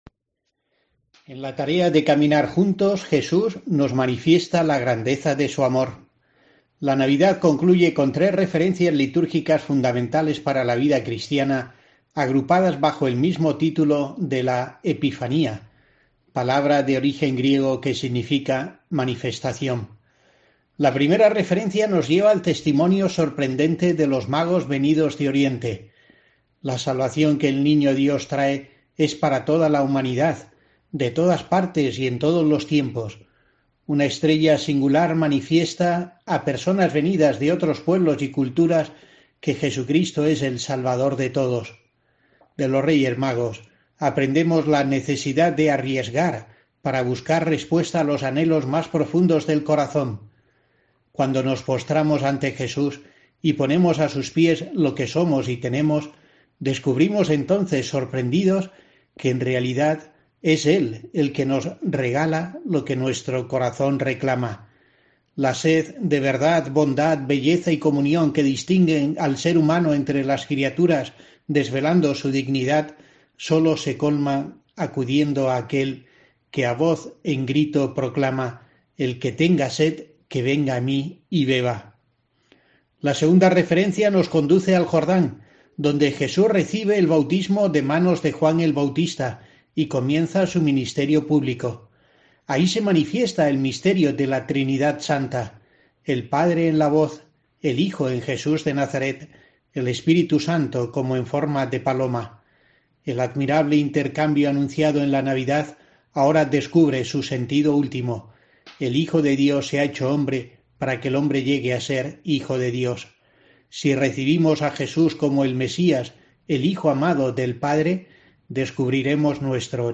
La Epifanía, que el obispo muestra reuniendo la Adoración al Niño, el Bautismo de Jesús y las Bodas de Caná, da contenido a la reflexión semanal del pastor asidonense